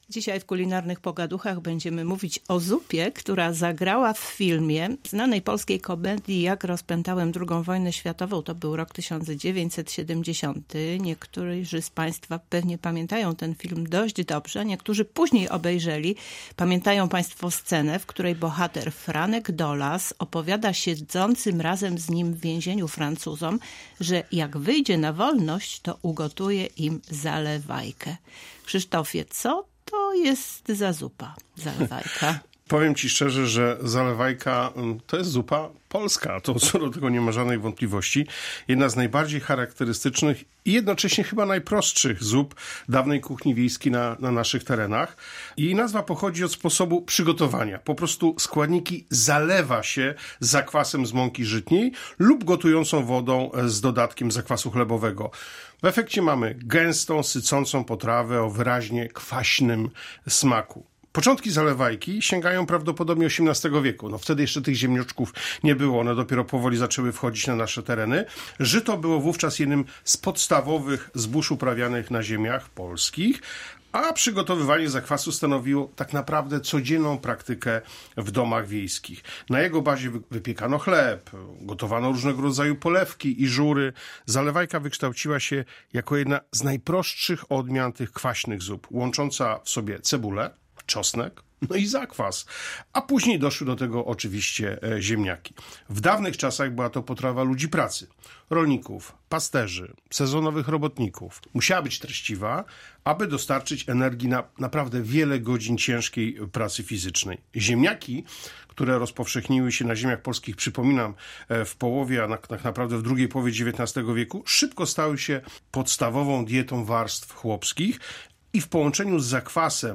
O historii tej tradycyjnej potrawy opowiadają twórcy audycji „Kulinarne pogaduchy” w Polskim Radiu Rzeszów.